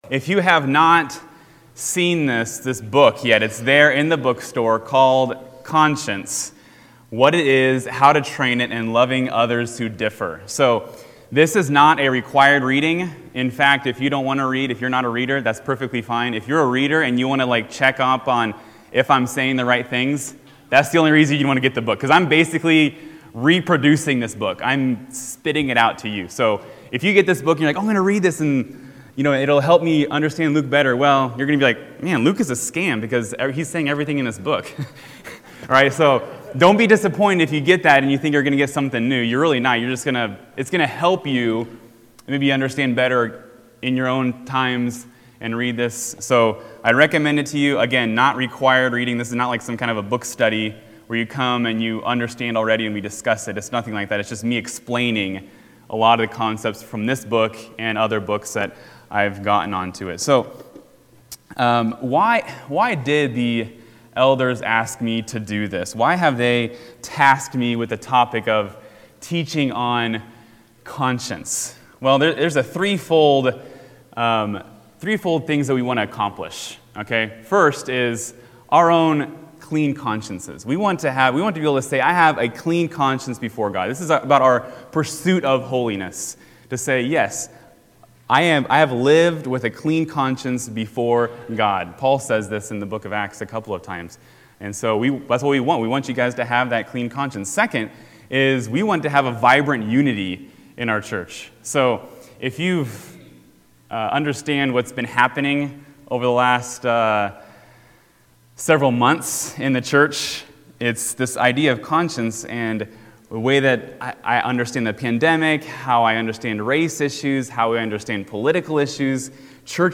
This opening lesson in a Sunday School series on the conscience gave some introductory understandings to what we’ll be talking about in the coming weeks.